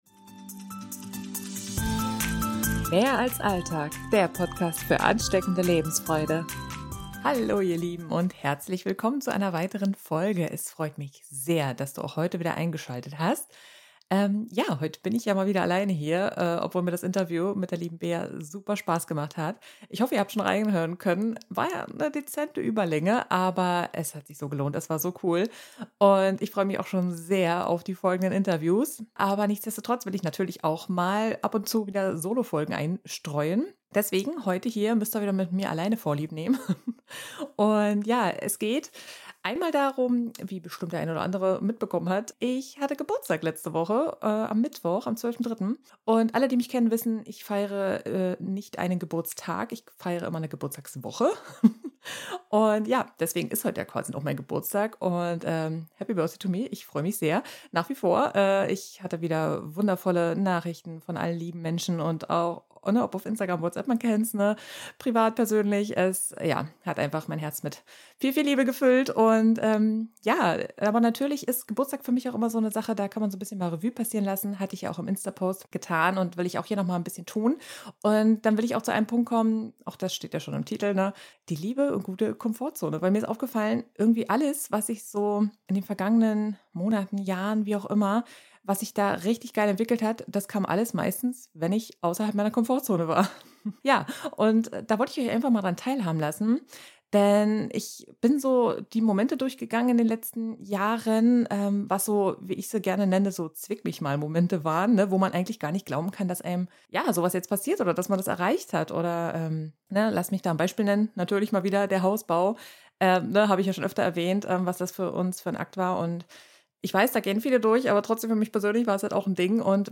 Warum es sich lohnt, die Komfortzone doch öfter mal zu verlassen, erzähle ich euch in der heutigen Folge! Intro-/Outromusik des Podcasts